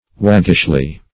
Waggish \Wag"gish\ (-g[i^]sh), a.